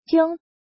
jiōng
jiong1.mp3